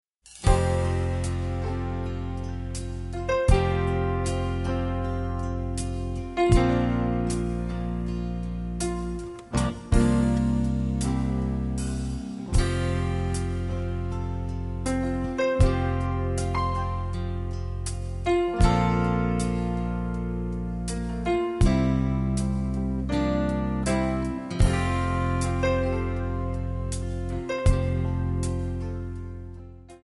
Backing track files: Country (2471)